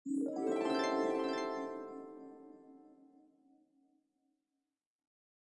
Download Flashback sound effect for free.
Flashback